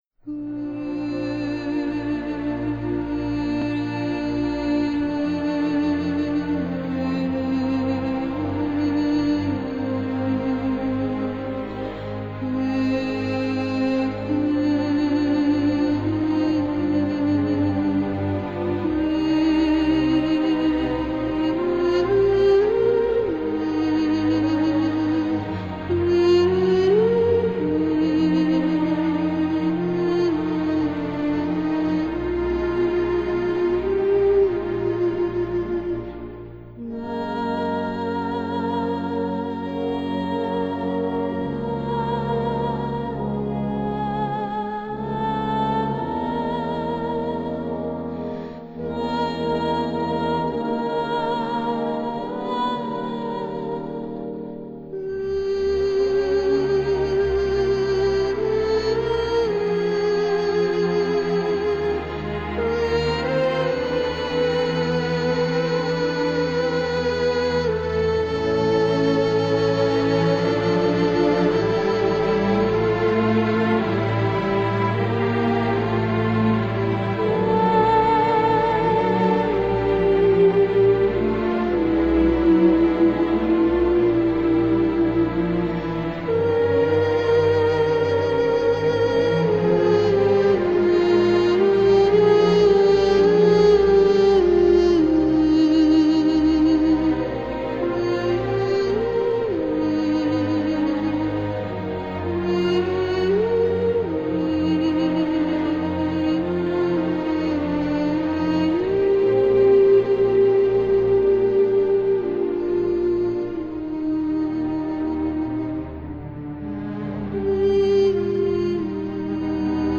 他们的风格更趋向于古典，像历史诗篇，像电影配乐。
这张唱片献给现代人的不只是优美的人声和旋律，它那神秘苍茫、飞扬壮丽的音乐内涵可以让苦难的心灵找到宁静祥和的庇护所。